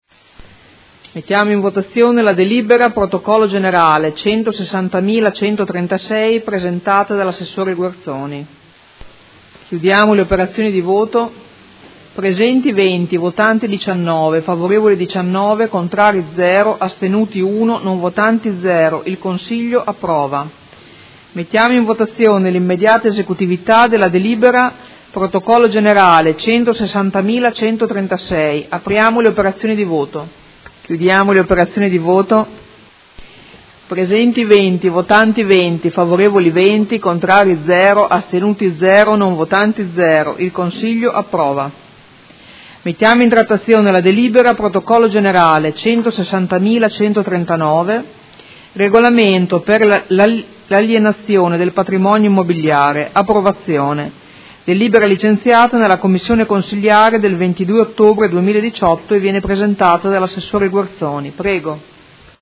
Presidente — Sito Audio Consiglio Comunale
Seduta dell’8/11/2018. Mette ai voti proposta di deliberazione: Concessione in diritto di superficie a favore del Comune di Formigine del Complesso immobiliare denominato “Pieve di Colombaro” posto a Formigine e cessione in piena proprietà aree stradali – Approvazione, e immediata esecutività